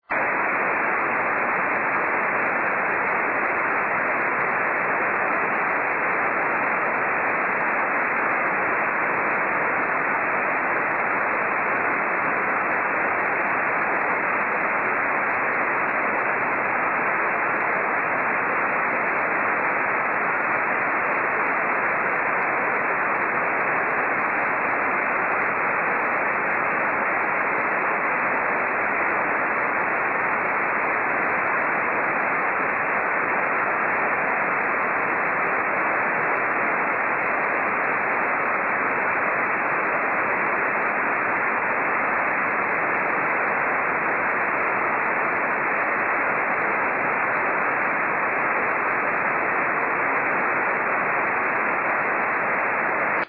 SSB: / WSJT JT65C　丹沢反射(69km)